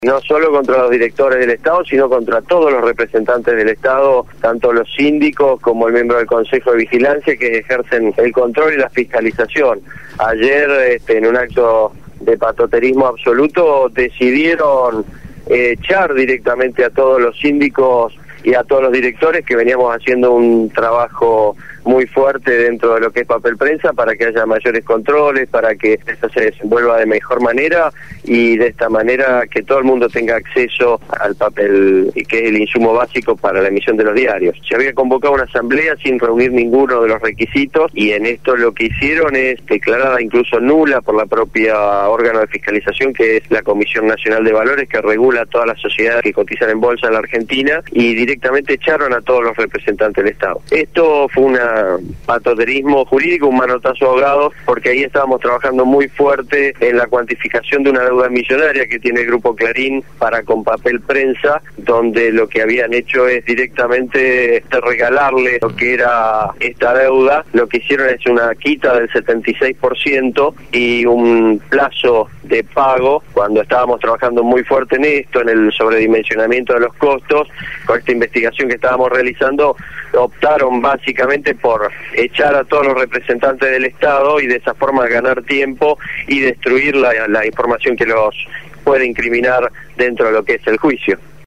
Daniel Reposo, Titular de la SIGEN «Sindicatura General de la Nacion»